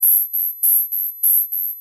am_wurlitzer_audio_4.wav